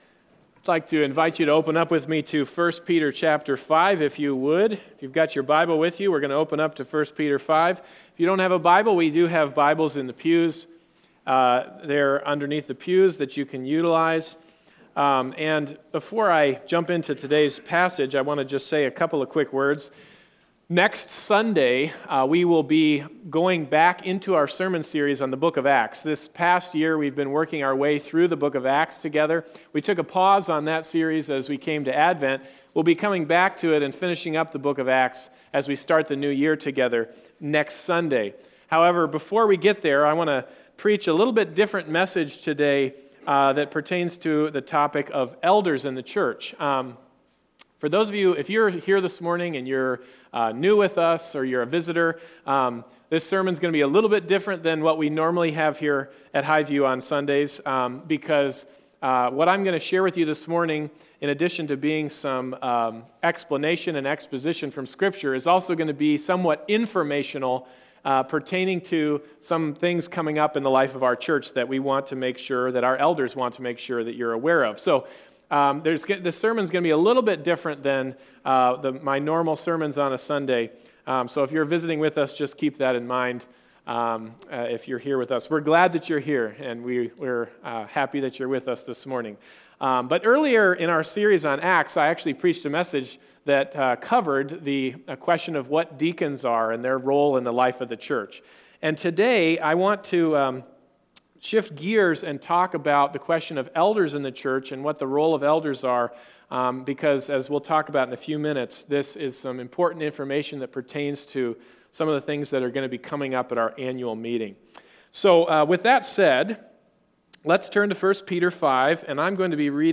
Bible Text: 1 Peter 5:1-5 | Preacher: